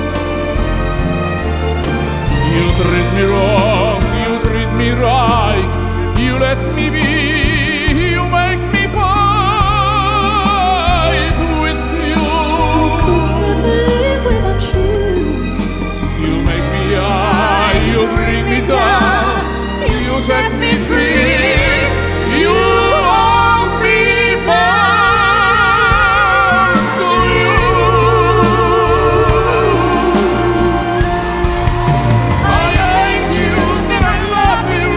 Струнные и рояль
Classical Opera Classical Crossover Opera
Жанр: Классика